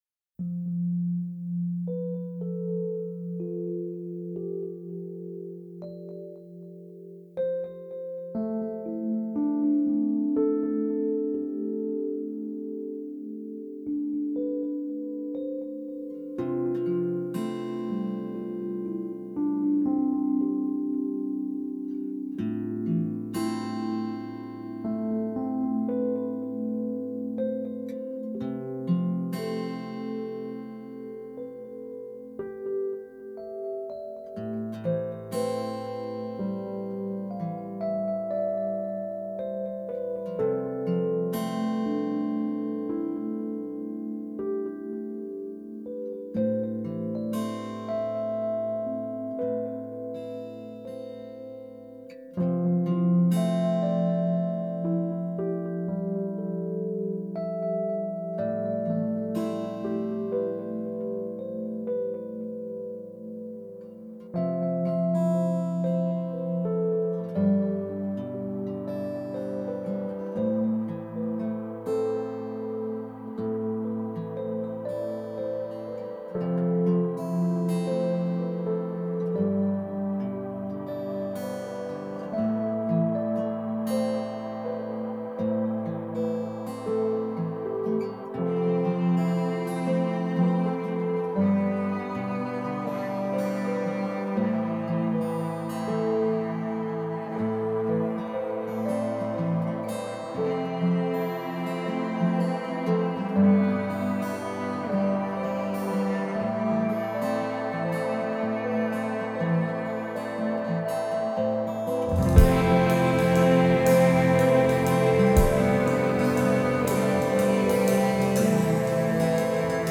Rock